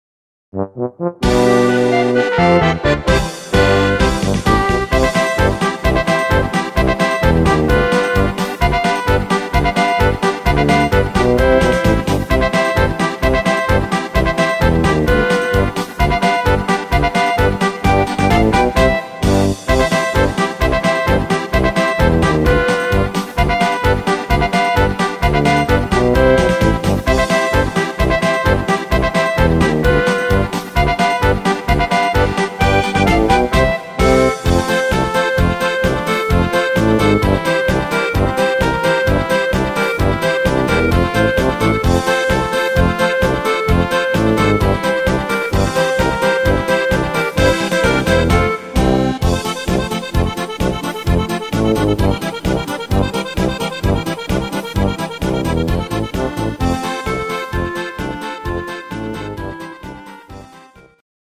instr. Trompete
Rhythmus  Polka
Art  Instrumental Trompete, Volkstümlich